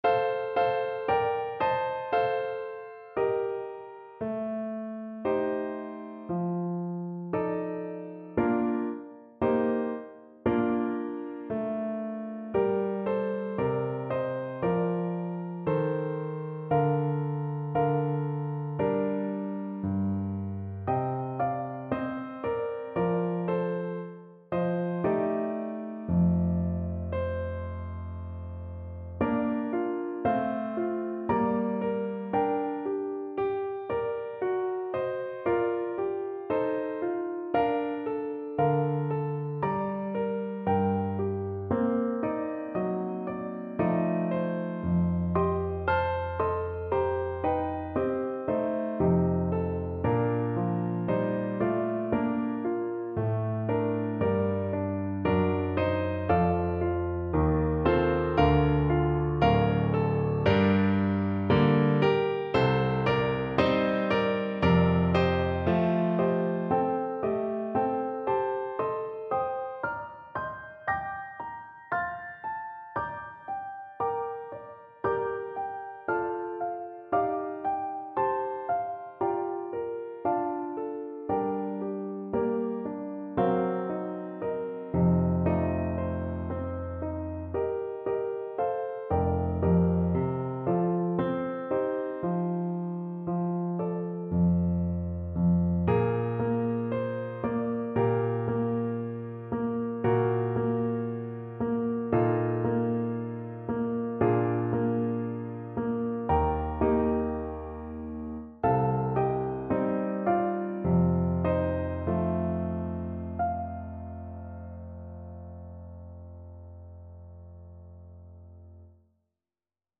Play (or use space bar on your keyboard) Pause Music Playalong - Piano Accompaniment Playalong Band Accompaniment not yet available transpose reset tempo print settings full screen
Clarinet
2/4 (View more 2/4 Music)
Bb major (Sounding Pitch) C major (Clarinet in Bb) (View more Bb major Music for Clarinet )
Moderato =80
Traditional (View more Traditional Clarinet Music)